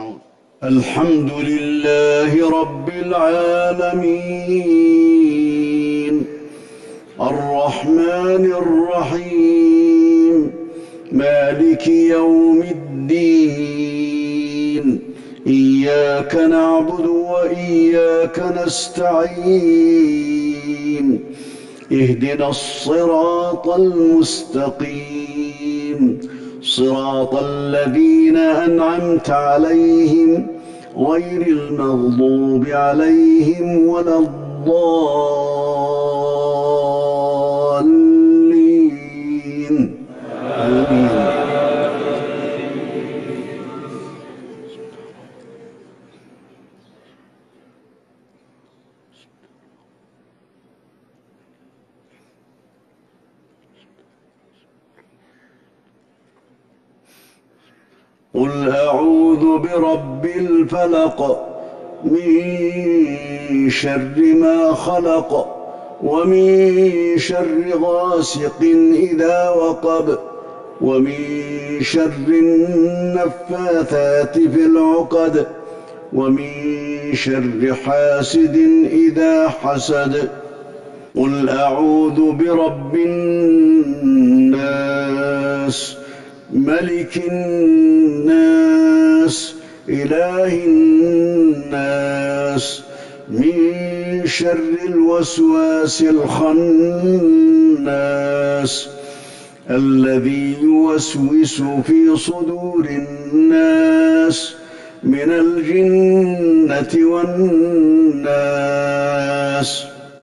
صلاة الجمعة ١٣ جمادي الاخره ١٤٤١هـ سورة الفلق والناس Friday prayer 7-2-2020 from Surah Al-Falaq and Alnaas > 1441 🕌 > الفروض - تلاوات الحرمين